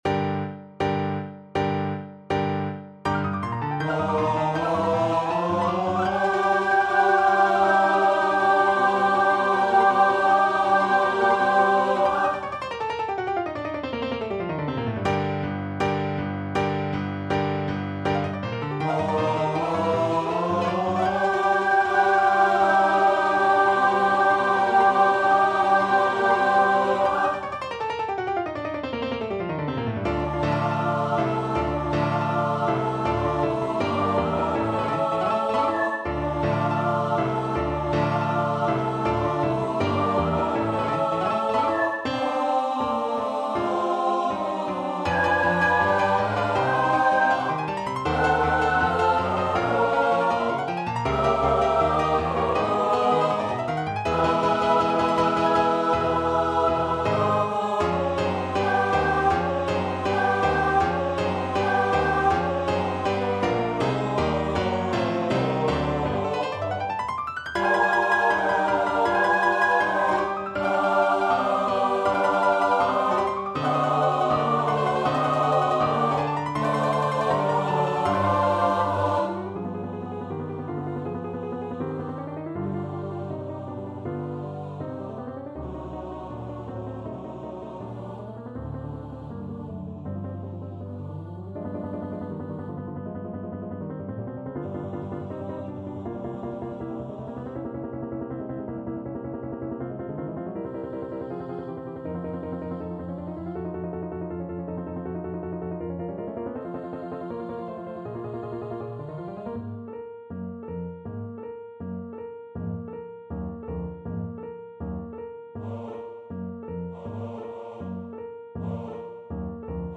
4/4 (View more 4/4 Music)
Allegro agitato (=80) (View more music marked Allegro)
Choir  (View more Intermediate Choir Music)
Classical (View more Classical Choir Music)